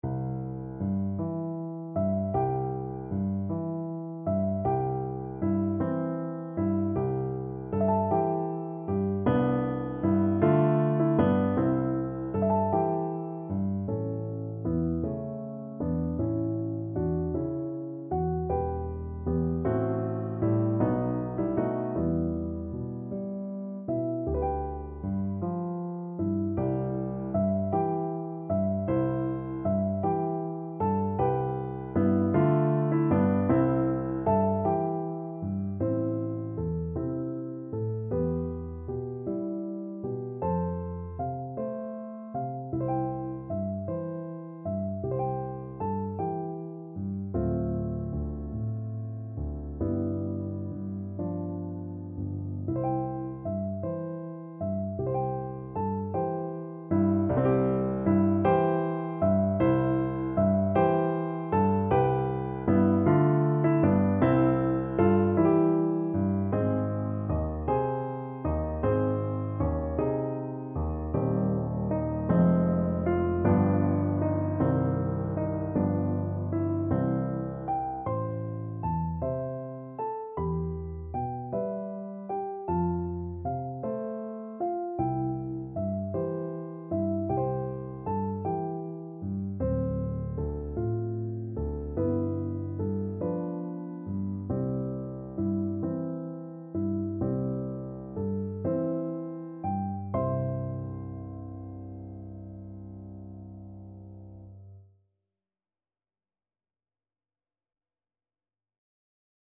6/8 (View more 6/8 Music)
~. = 52 Allegretto